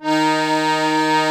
D#3 ACCORD-R.wav